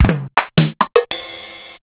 Casio RZ-1 8000Hz multisample for kit in keys mode. OCT C4. sound nice with some time and pitch shifts. (only tested on EP-40)